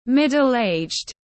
Người trung niên tiếng anh gọi là middle-aged, phiên âm tiếng anh đọc là /ˌmɪd.əlˈeɪdʒd/.
Middle-aged /ˌmɪd.əlˈeɪdʒd/